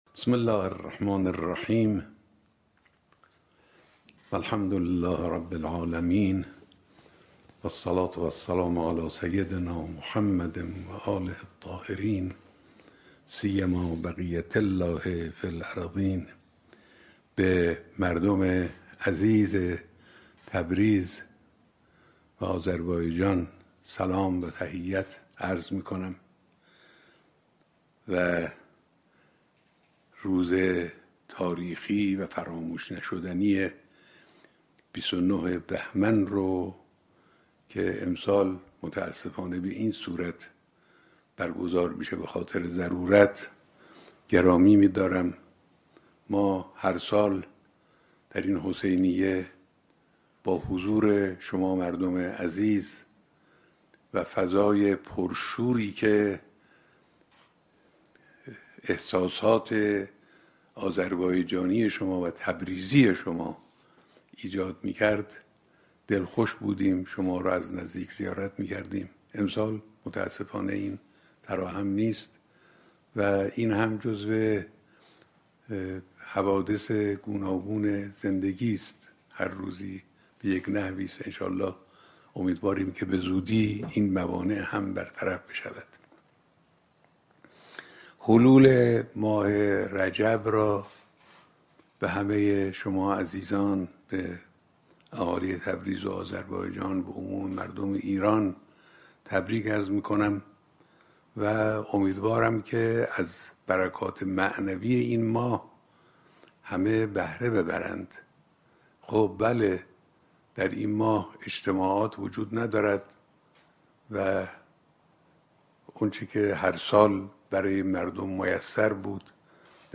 در شرایط كرونایی از دعا‌های ماه رجب در خانه‌ها غفلت نشود رهبر معظم انقلاب اسلامی در دیدار تصویری با مردم تبریز به مناسبت سالروز قیام 29 بهمن در سال 1356؛ تاكید كردند: در شرایط كرونایی و تعطیلی اجتماعات از دعا‌های ماه رجب در خانه‌ها غفلت نشود.